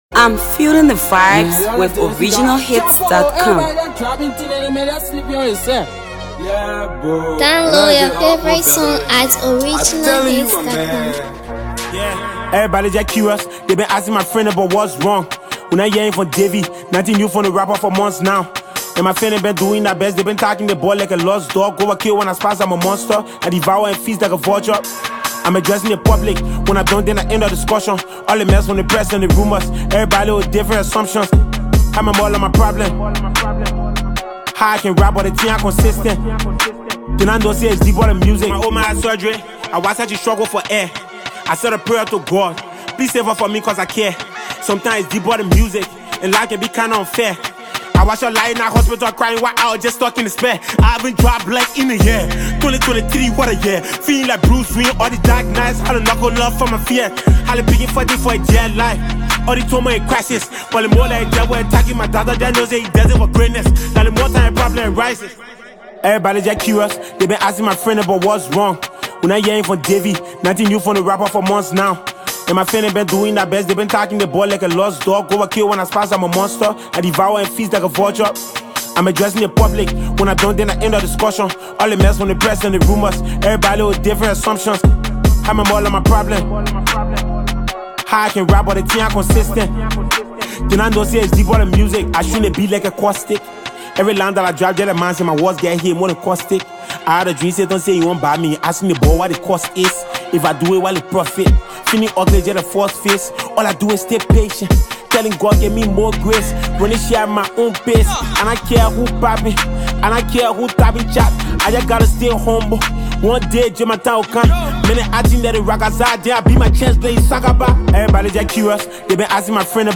hardcore verses